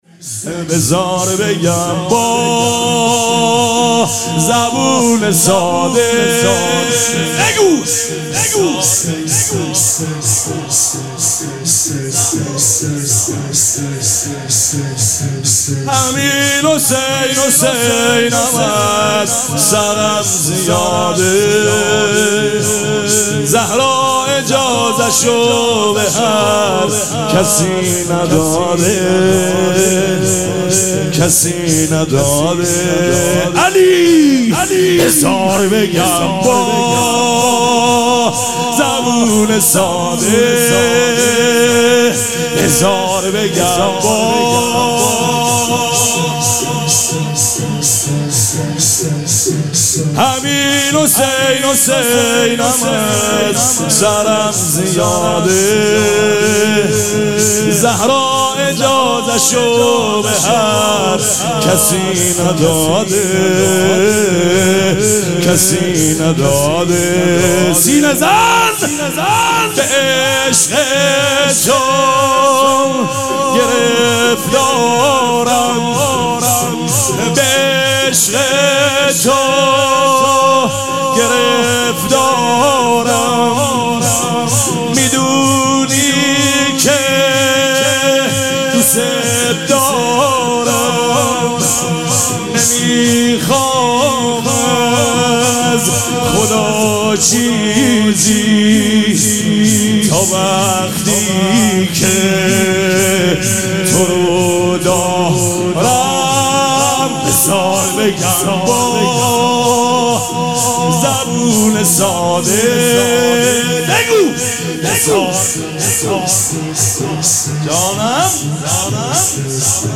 شب دوم مراسم عزاداری اربعین حسینی ۱۴۴۷
شور
مداح